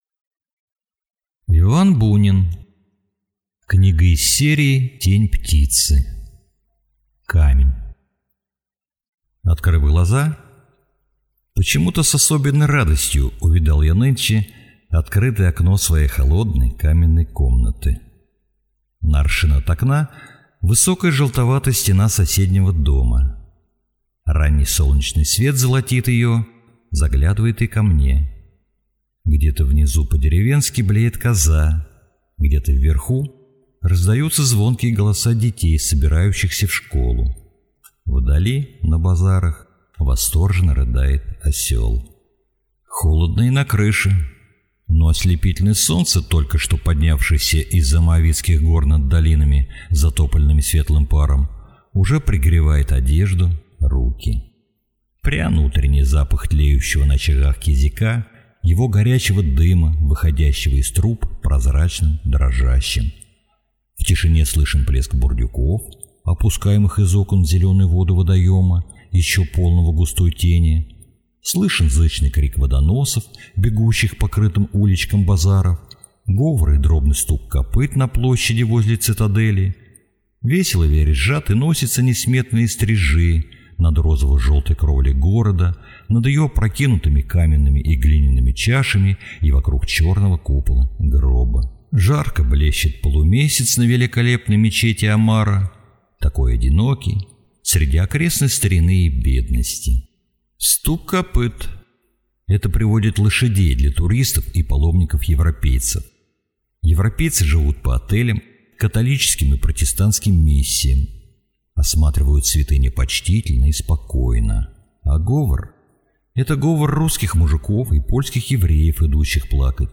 Аудиокнига Камень | Библиотека аудиокниг